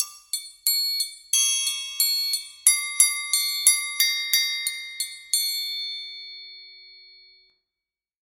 Звуки музыкального треугольника
Простая мелодия для игры на треугольнике